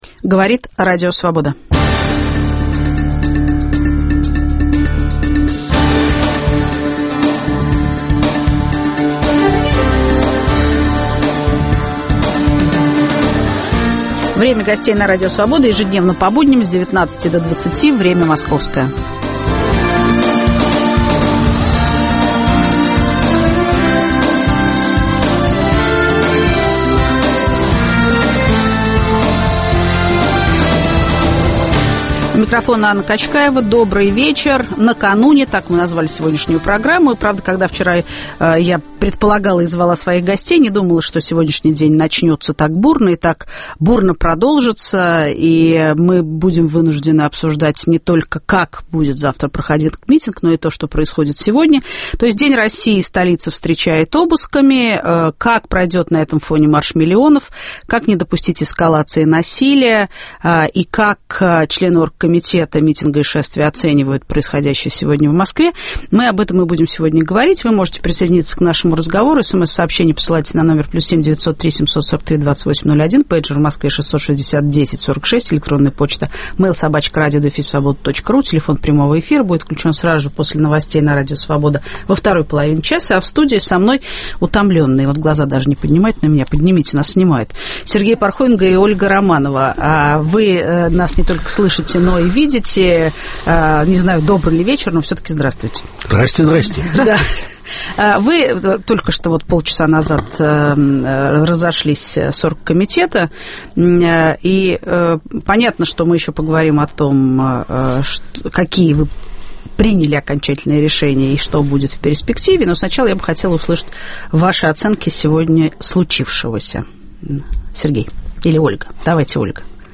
В студии - Сергей Пархоменко и Ольга Романова.